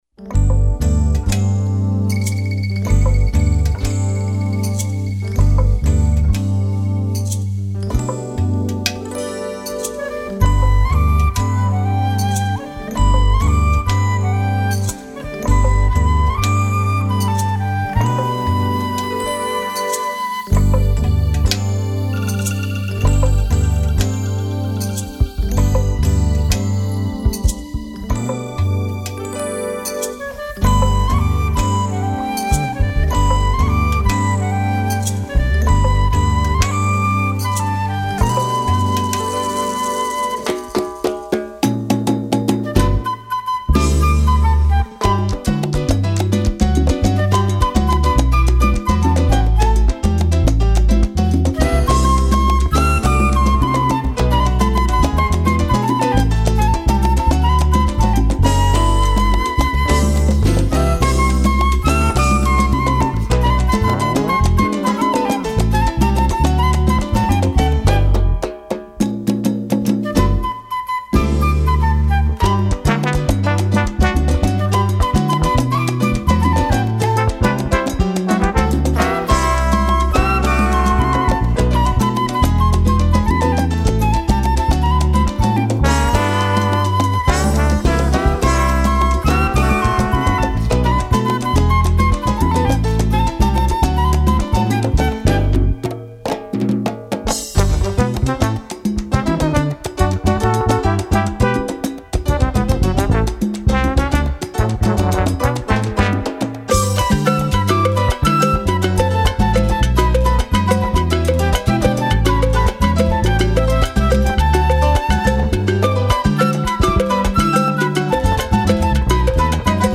Música popular: instrumental y jazz